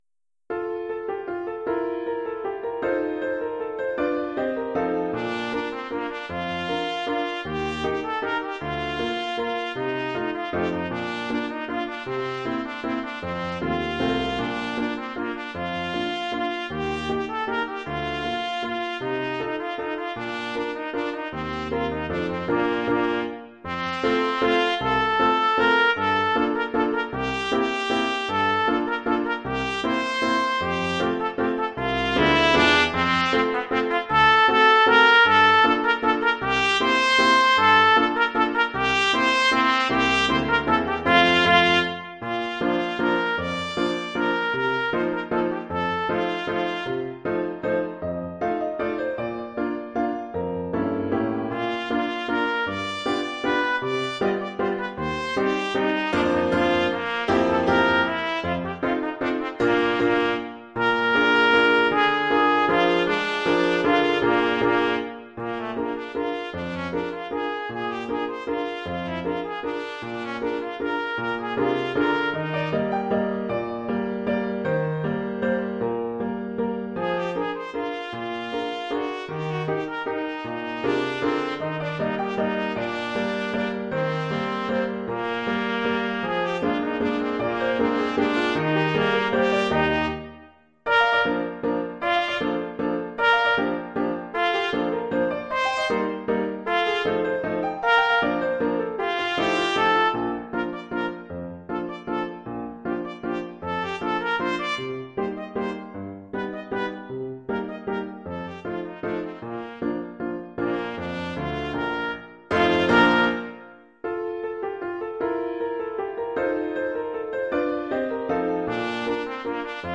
Oeuvre pour trompette ou cornet
ou bugle et piano.